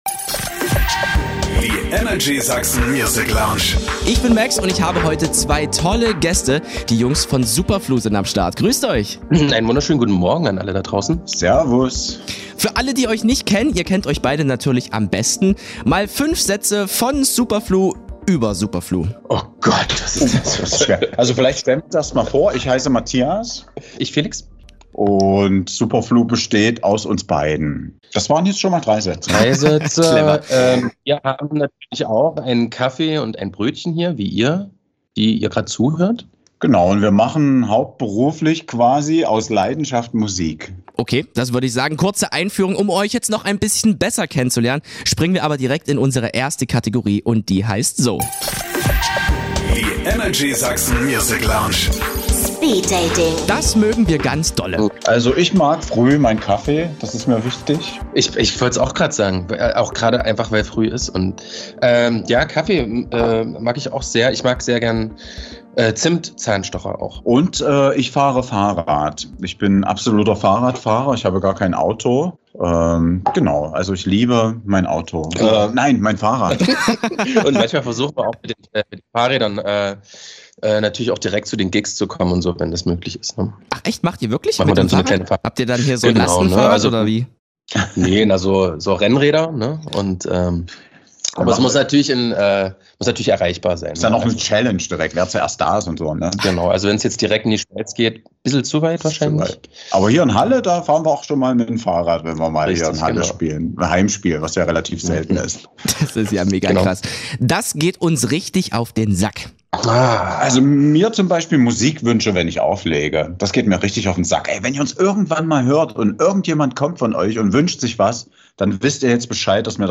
Zwei Hallenser Boys, die im Genre „Superhouse" zu Hause sind.